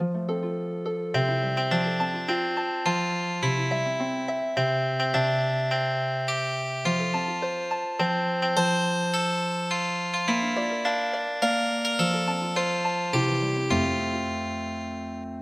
癒しのハープ風